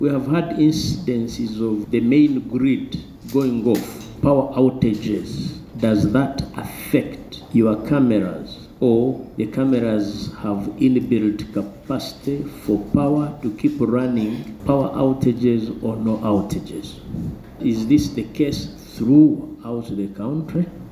Committee Chairperson, Hon. Wilson Kajwengye asked about the ability of the cameras to operate efficiently during power outages affecting different parts of the country.